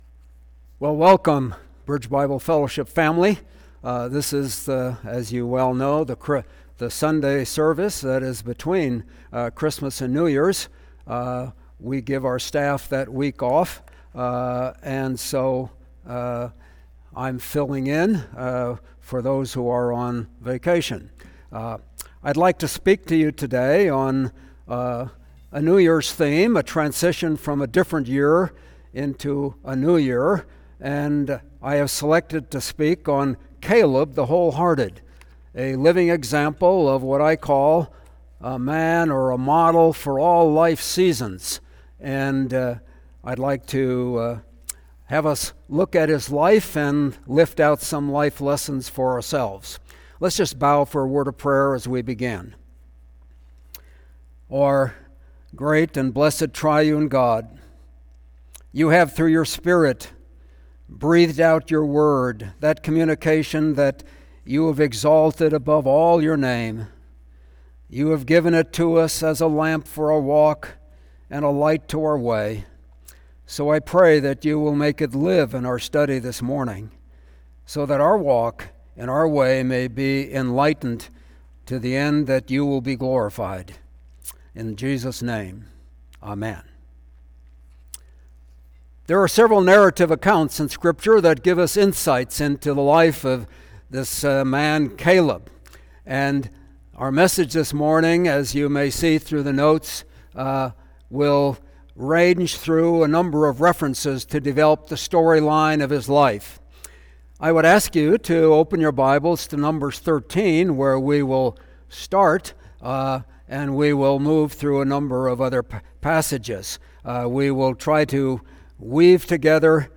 Service Type: Sunday Worship Service